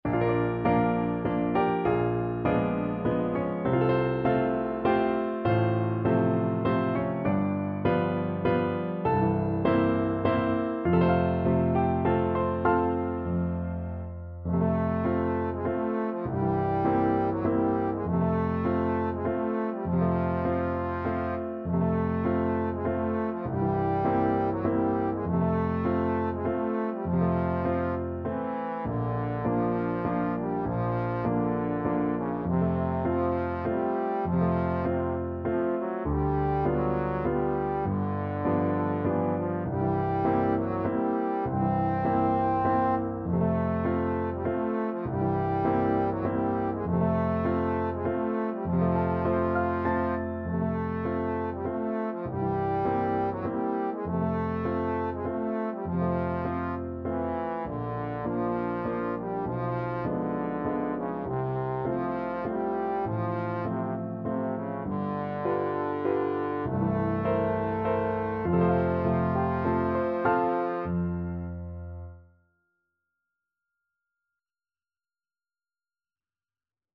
~ = 100 Slowly and dreamily
3/4 (View more 3/4 Music)
Classical (View more Classical Trombone Music)